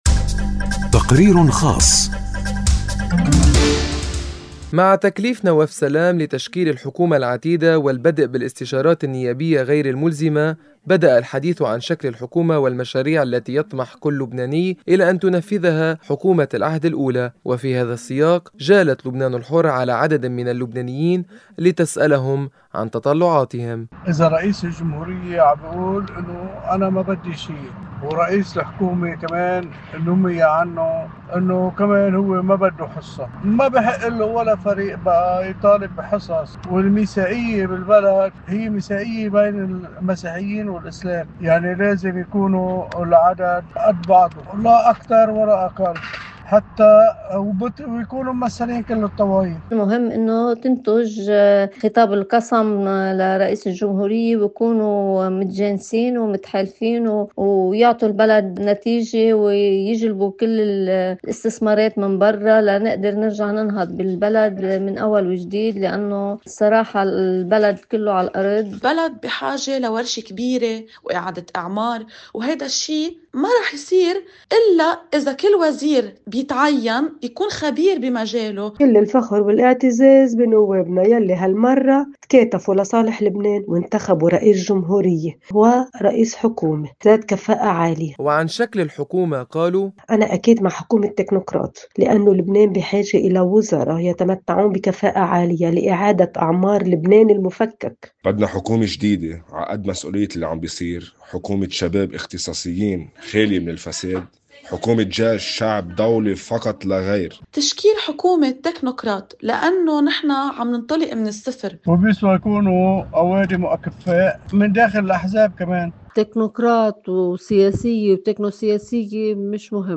وفي هذا السّياق، جالت “لبنان الحرّ” على عددٍ من اللّبنانيّين لتسألهم عن تطلّعاتهم.